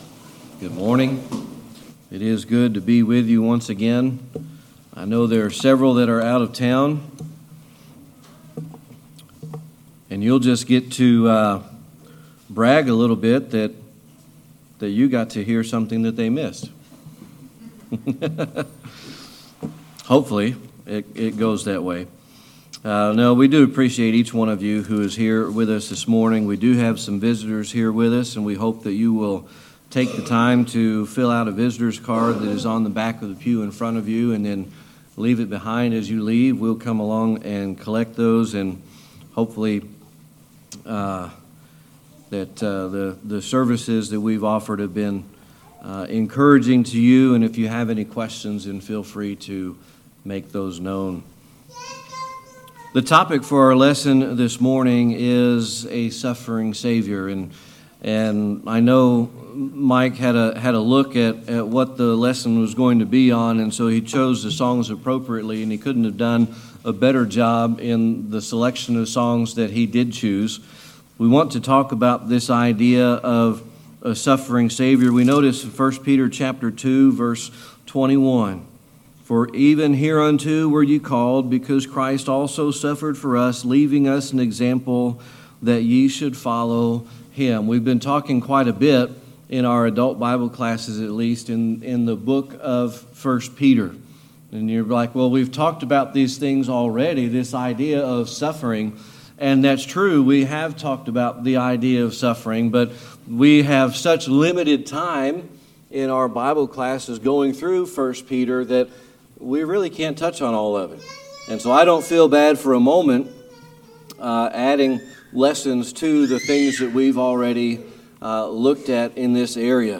1 Peter 2:21 Service Type: Sunday Morning Worship The topic for our lesson this morning is The Suffering Savior.